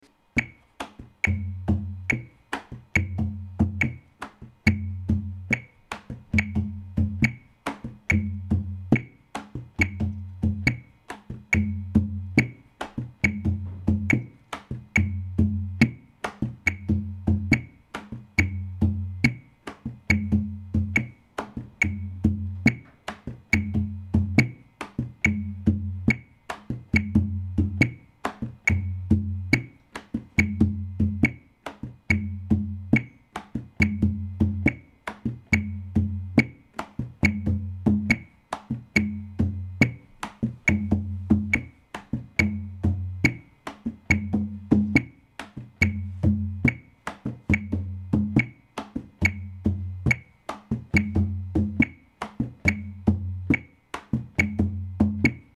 rebolo variation tempo=70
rebolo_70_variation.mp3